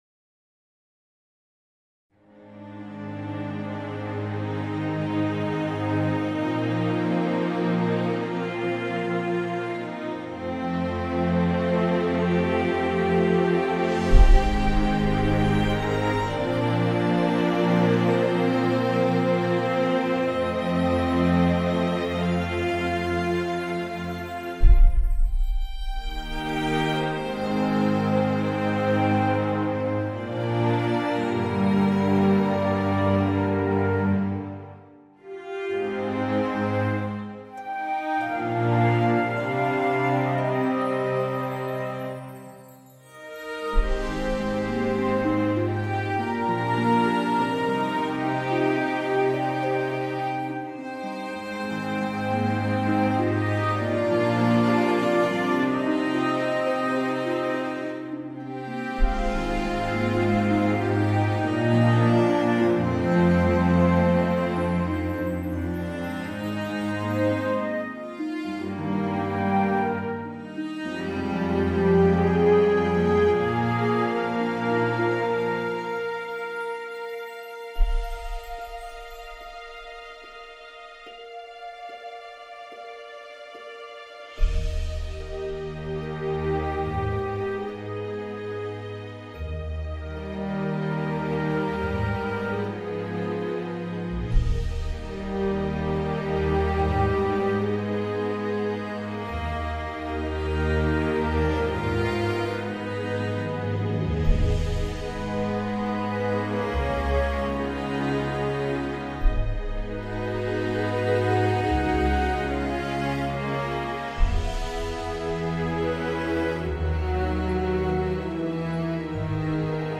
I've tried here to create an atmosphere of dusk Ponyville.